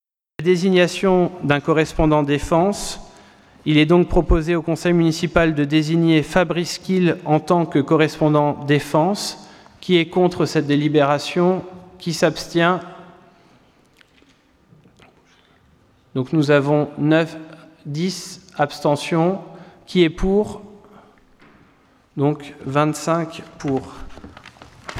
Point 18 : Désignation d’un correspondant défense Conseil Municipal du 04 juillet 2020